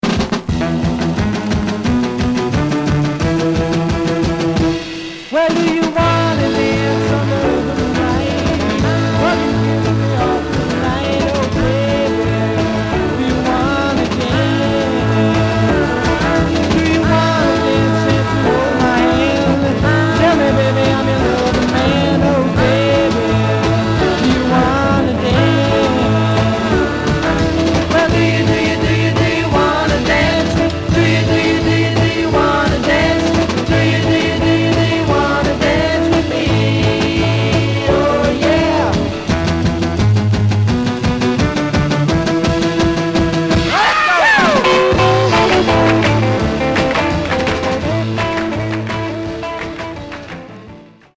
Surface noise/wear
Mono
Garage, 60's Punk